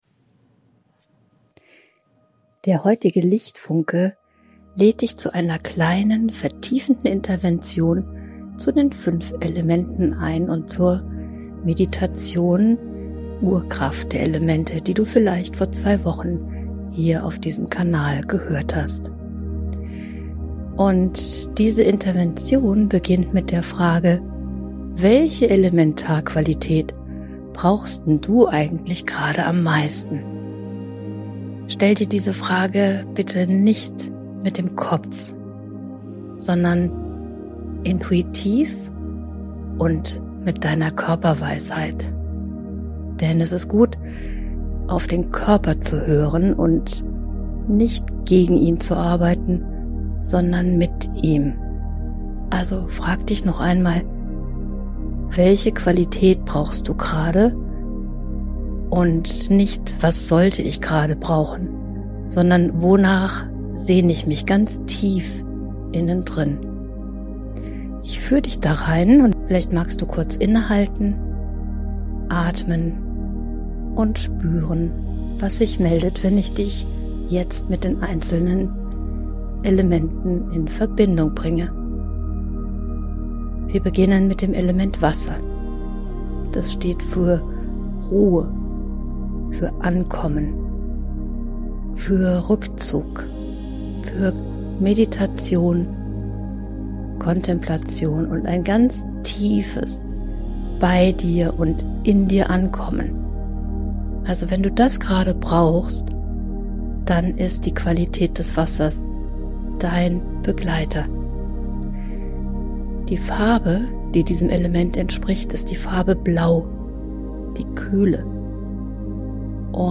Nicht als lange Meditation, sondern als kurze Übung zum Erspüren, welches Element du gerade am meisten brauchst.
Am besten mit Kopfhörern hören.